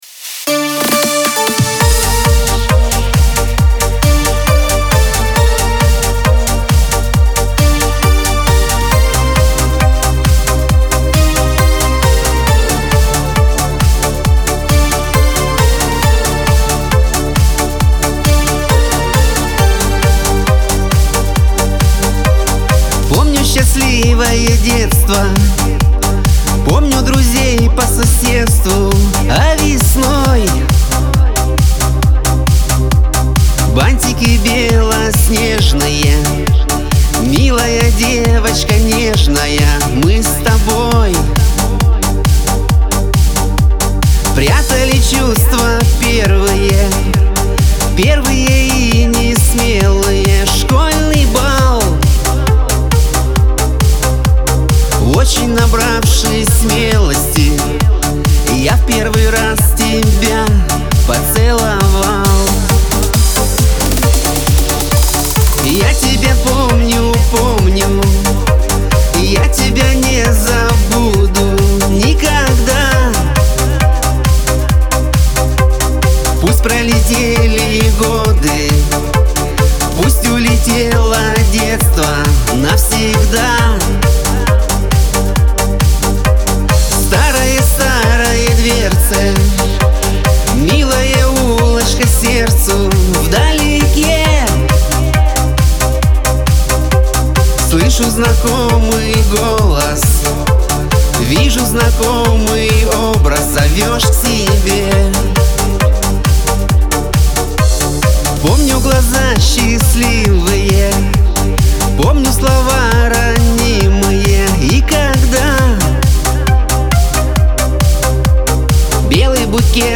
диско
Лирика